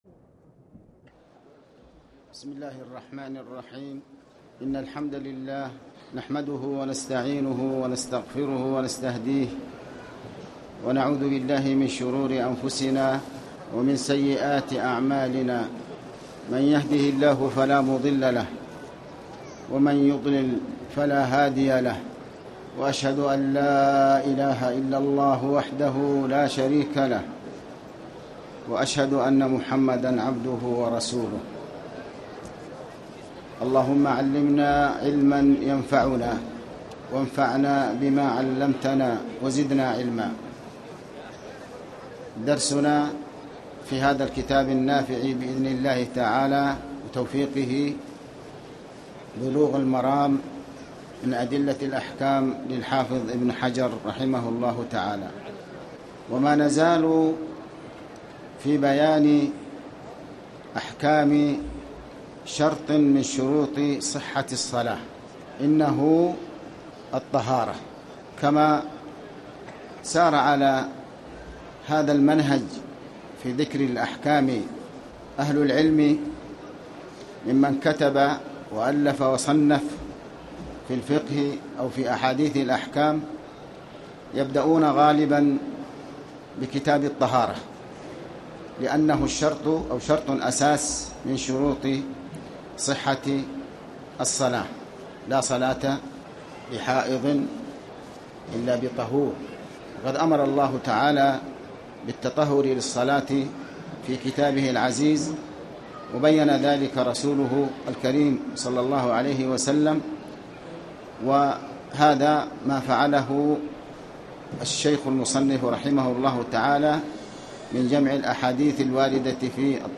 تاريخ النشر ٢٧ صفر ١٤٣٩ هـ المكان: المسجد الحرام الشيخ